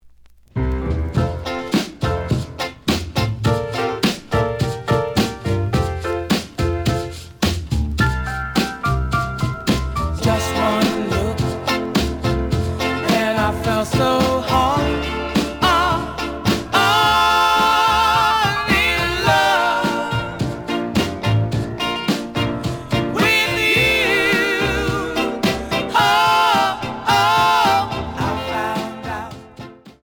The audio sample is recorded from the actual item.
●Genre: Soul, 60's Soul
Edge warp.